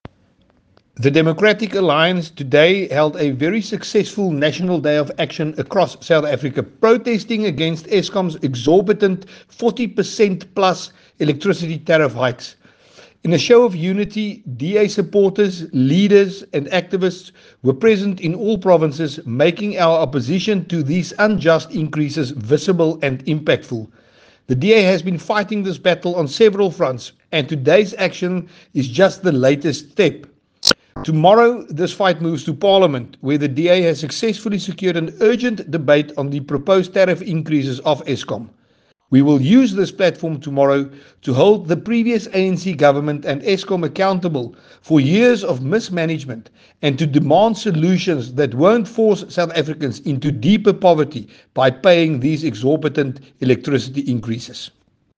English and Afrikaans soundbite by Willie Aucamp MP.